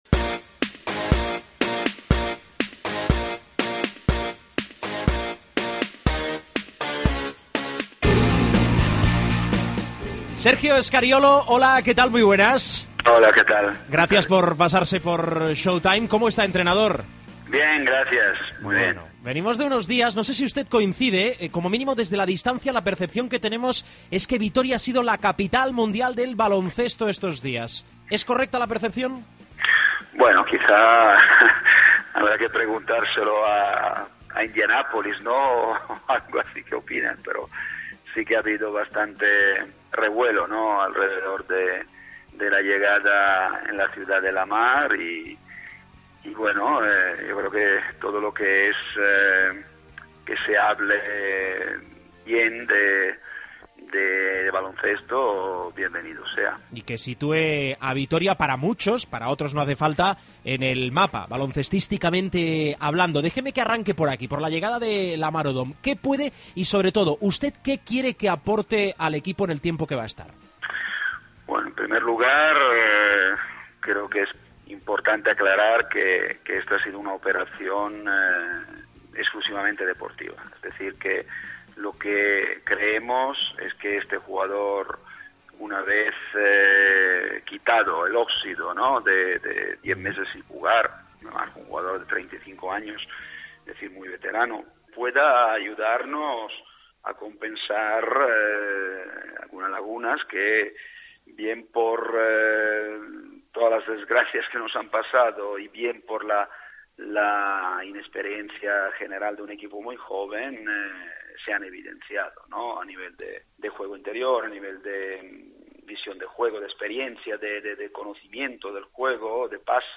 Entrevista a Sergio Scariolo